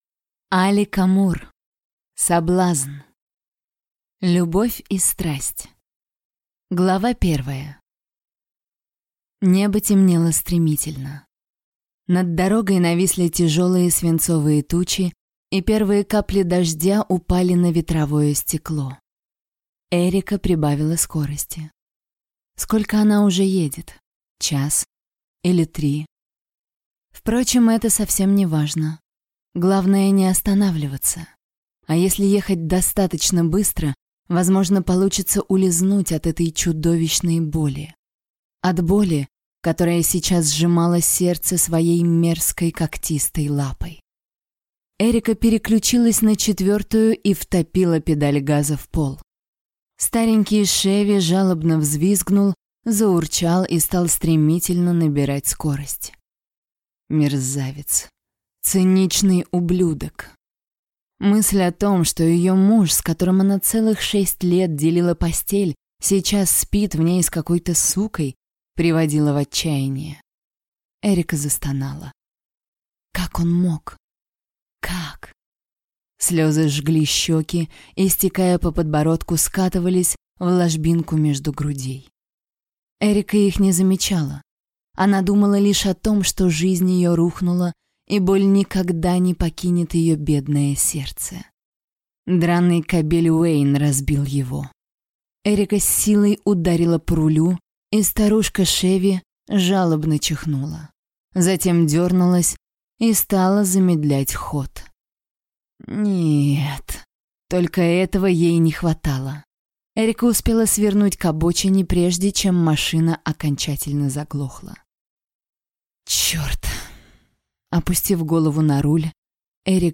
Аудиокнига Соблазн | Библиотека аудиокниг